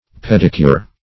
Pedicure \Ped"i*cure\, n. [Pedi- + L. cura care.]